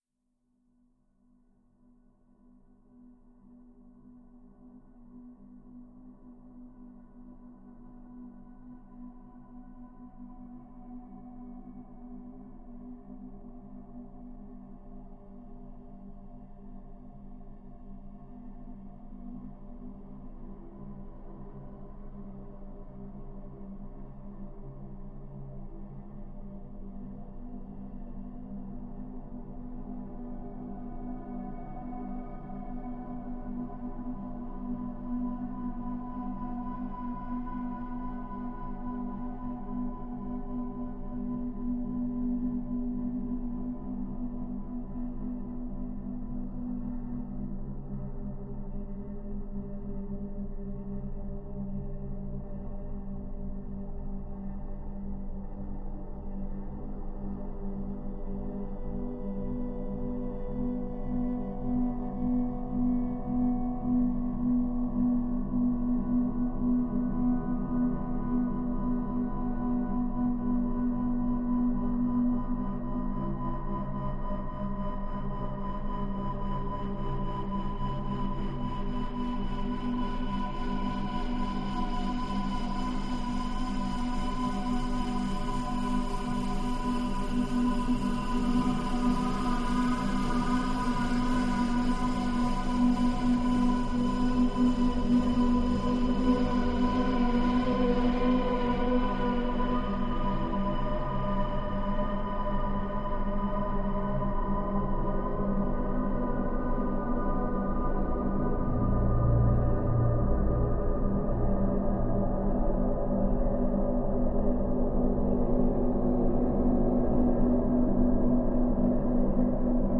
Reimagined version